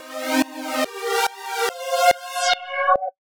Index of /musicradar/uk-garage-samples/142bpm Lines n Loops/Synths
GA_SacherPad142C-01.wav